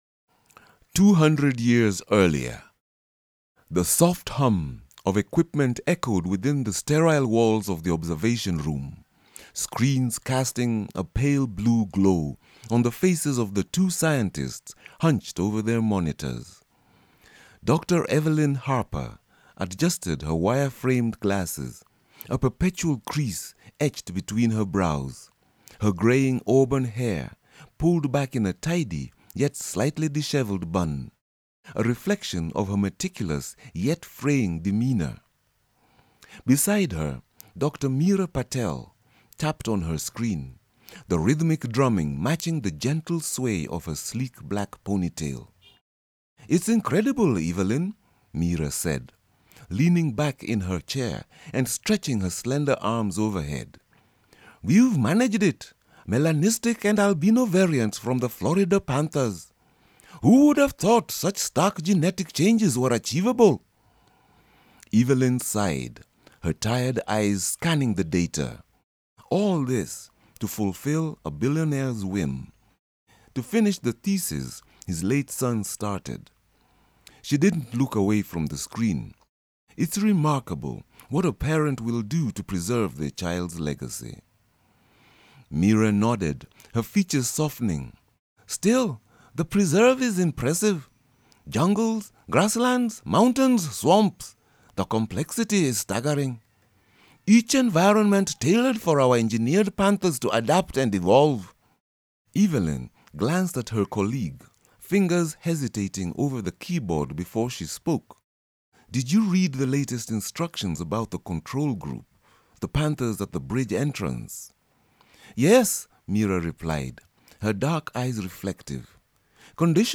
Voice demo 1
I do voice-overs